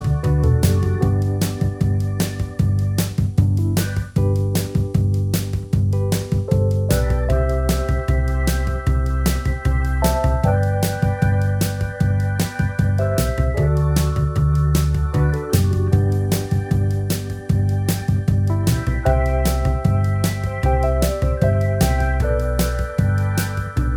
Minus All Guitars Soft Rock 3:12 Buy £1.50